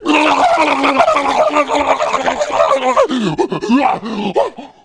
1 channel
BLUE-ELECTROCUTE1.WAV